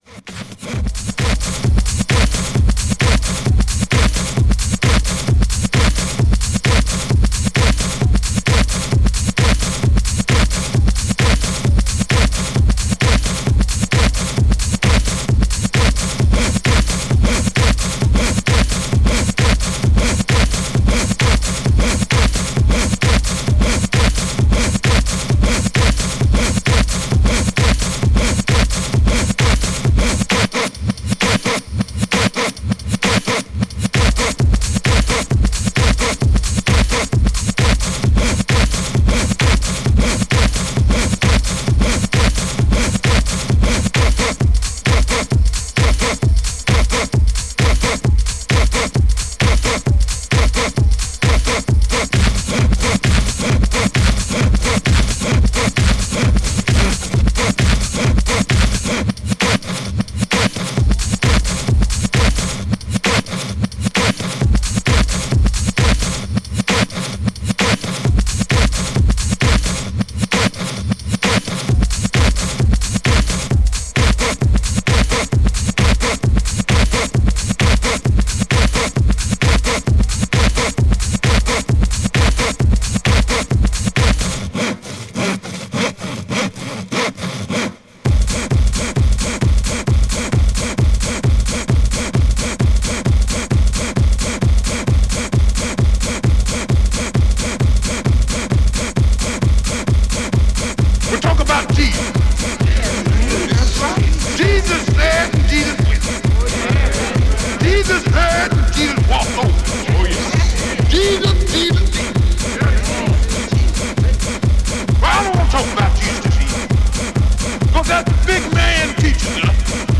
手鋸で木を切る摩擦ノイズがサディスティックなハードテクノに落とし込まれた